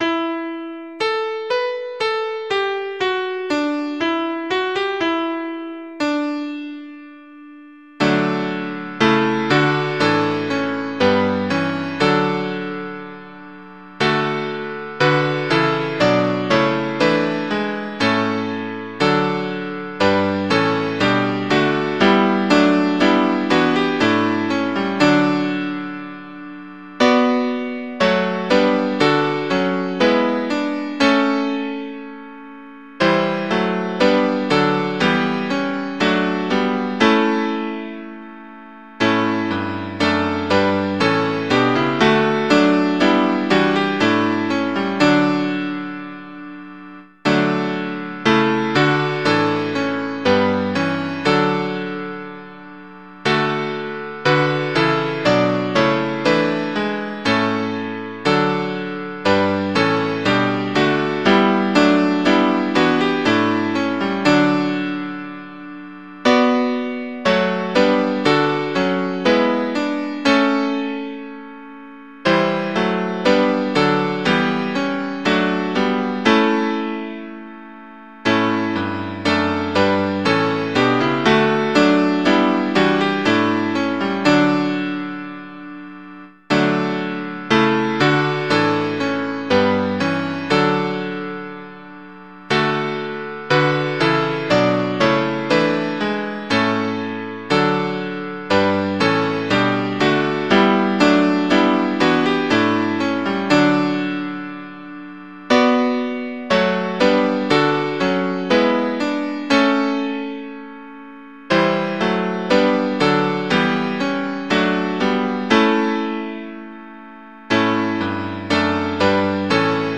Mp3 Audio of Tune Abc source